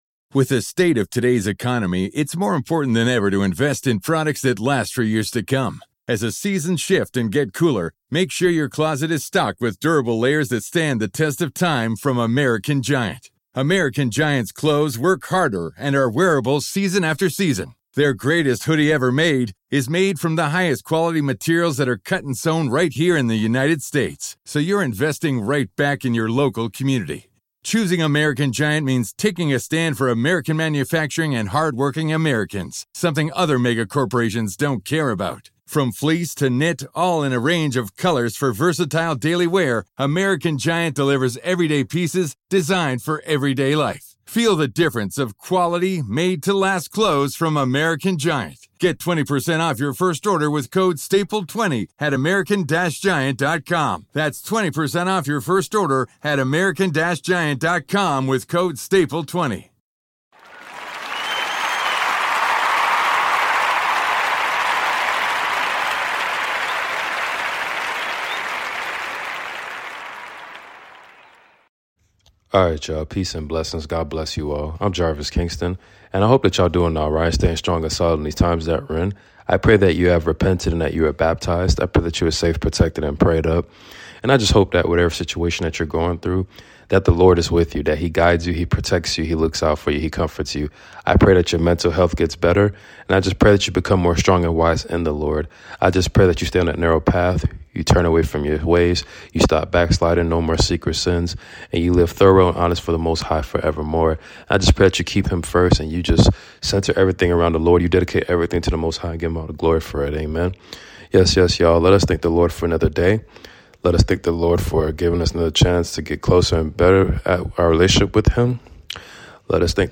Book of Philippians reading completion chapters 1-4! Amen !